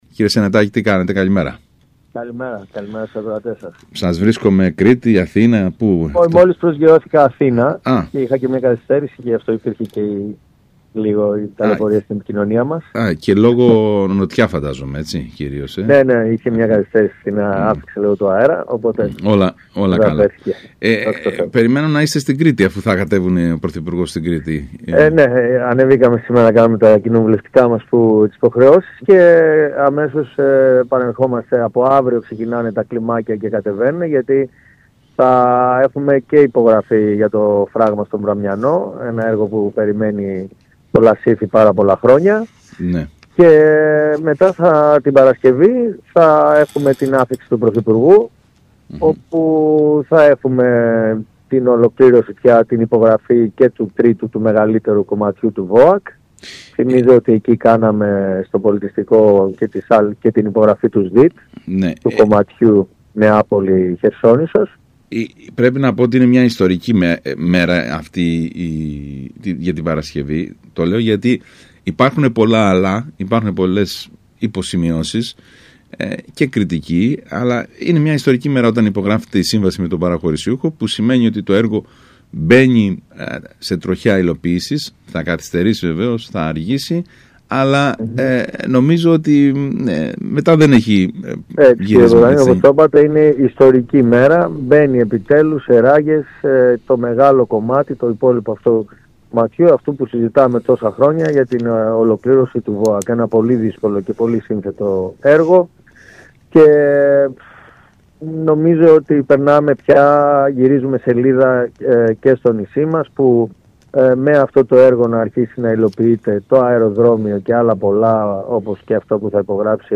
μιλώντας στον ΣΚΑΙ Κρήτης